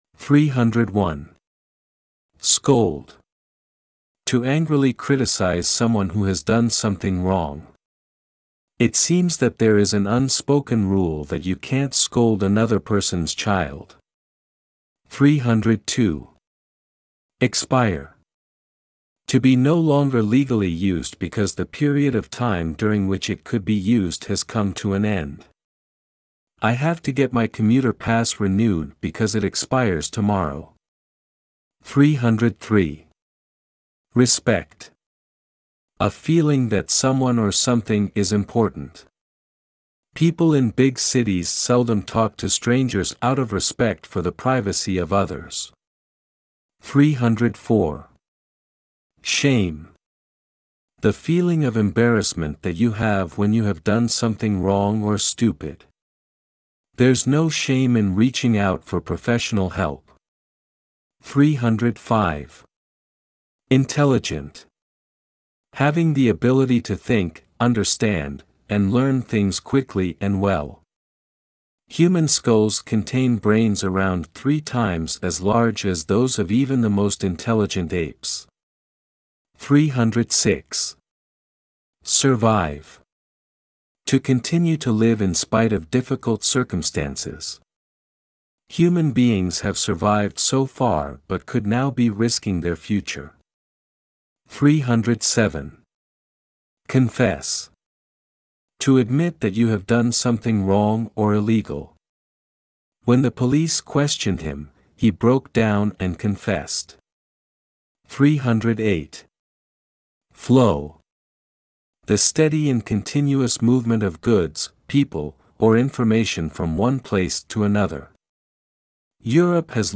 ネイティブ音声が共通テスト英語キーワードを単語・英英定義・例文の順で読み上げるyoutube【共通テスト英語キーワード820】共通テスト英語キーワード820単語・英英定義・例文音声を作成しました。
22日目(301)~(380) ※ (301)~(380)単語・英英定義・例文音声 ※英単語を転記し選んだ訳語を記入してください。